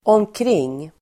Uttal: [åmkr'ing:]